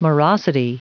Prononciation du mot morosity en anglais (fichier audio)
Prononciation du mot : morosity